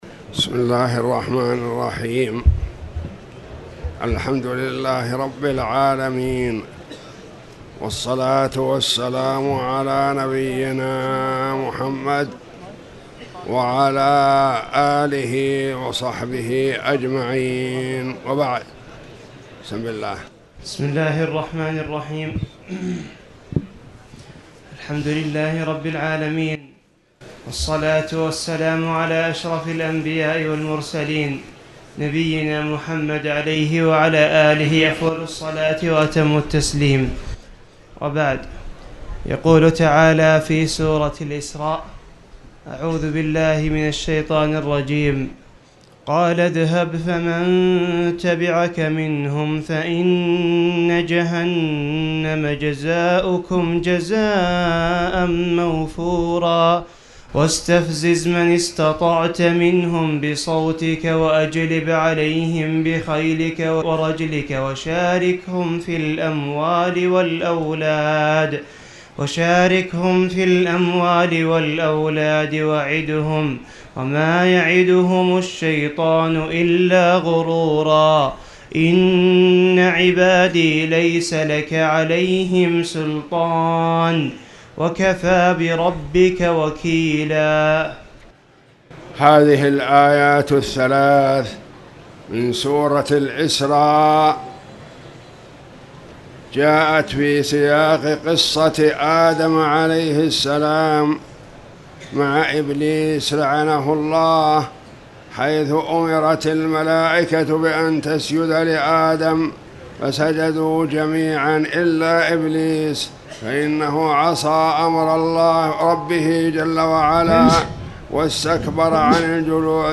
تاريخ النشر ٢٥ رمضان ١٤٣٧ هـ المكان: المسجد الحرام الشيخ